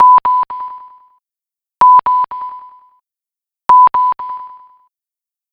3pings.wav